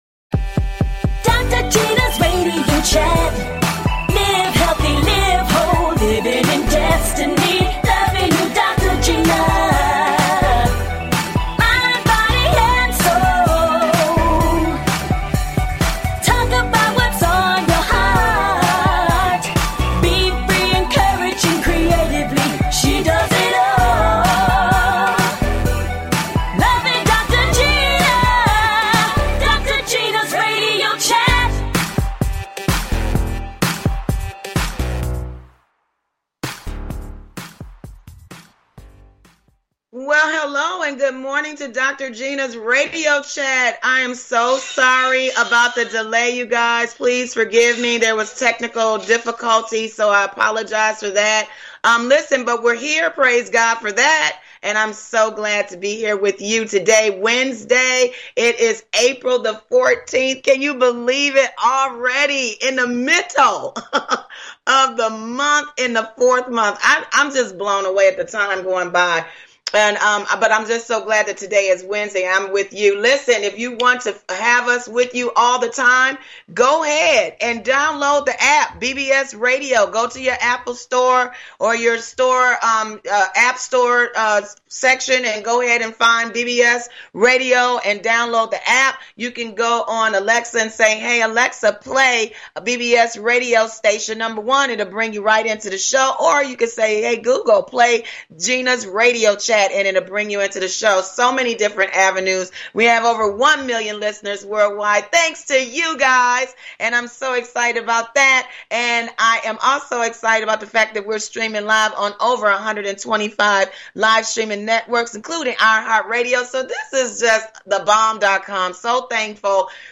And full of laughter!
A talk show of encouragement.